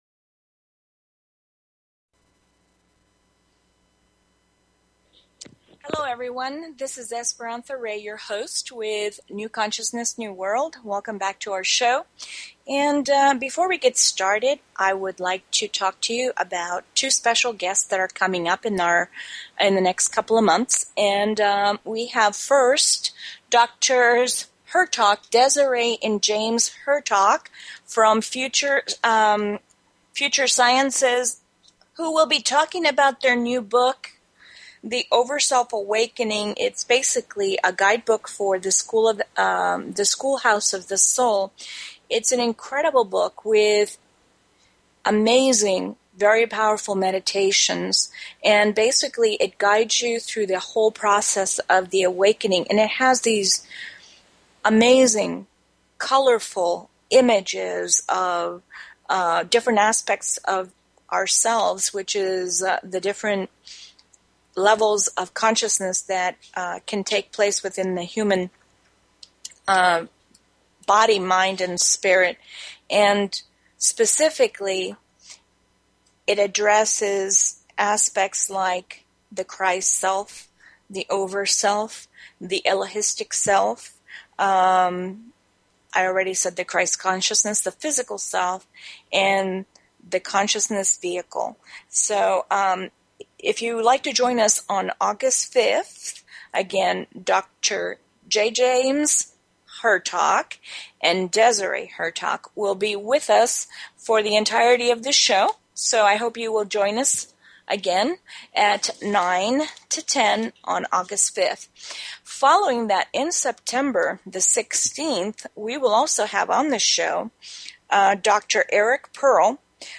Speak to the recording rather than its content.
This will be accomplished through a series of discussion, interviews with other experts, teaching techniques to help in this process, and audience participation through questions and answers.